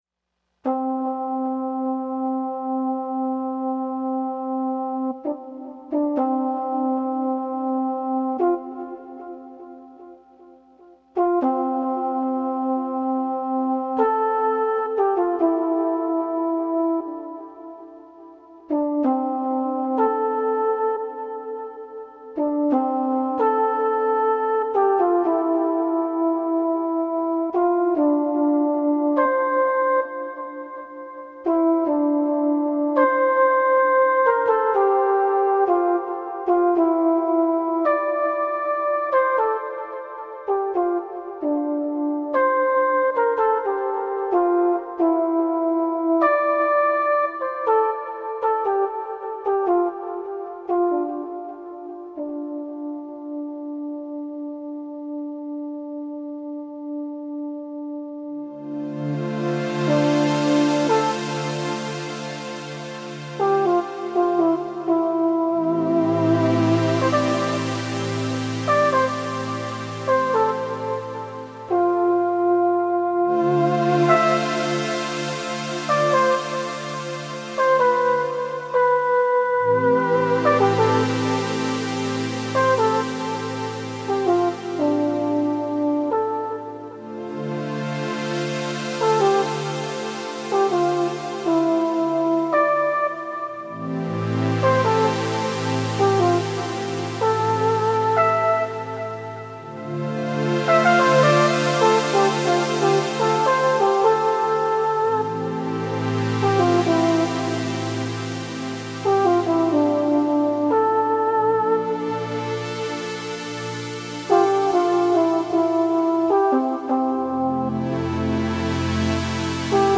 Synthesizer Improvisations
recorded at home, mid-August 2011